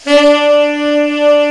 Index of /90_sSampleCDs/Giga Samples Collection/Sax/HARD + SOFT
TENOR SOFT.1.wav